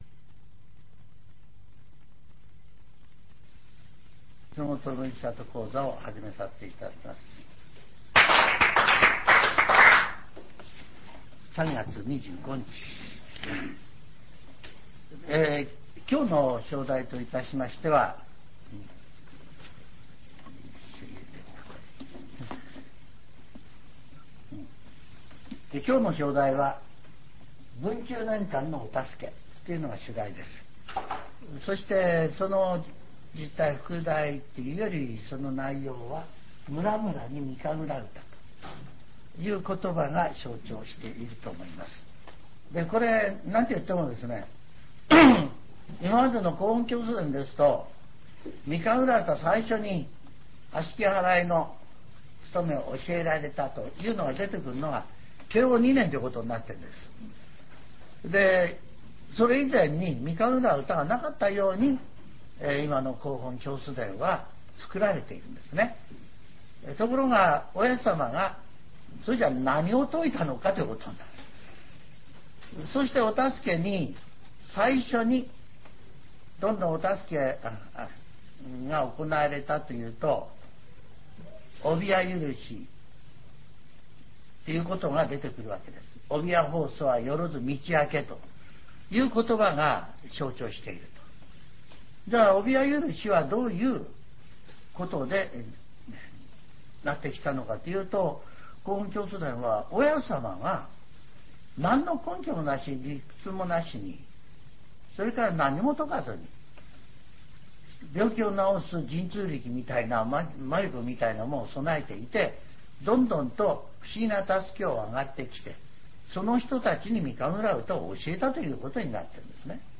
全70曲中19曲目 ジャンル: Speech